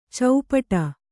♪ caupaṭa